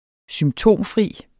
Udtale [ -ˌfʁiˀ ]